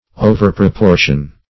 Overproportion \O`ver*pro*por"tion\, v. t. To make of too great proportion.